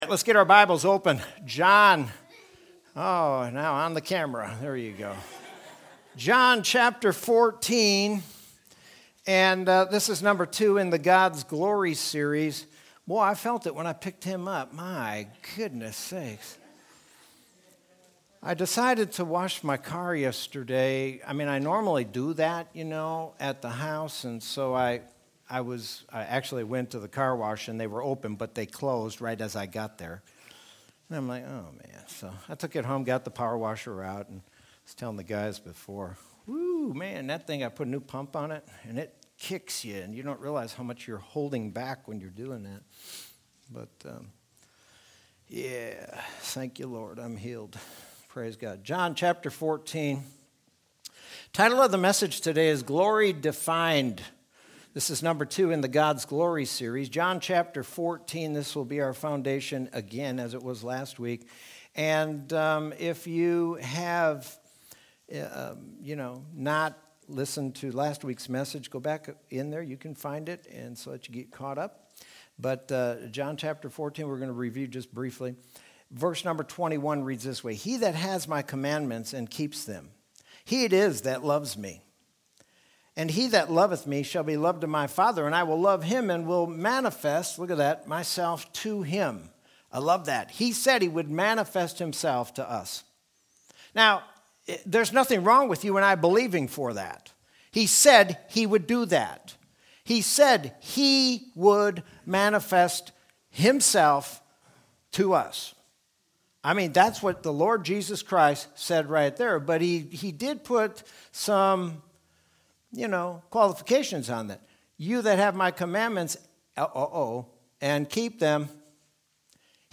Sermon from Sunday, February 28th, 2021.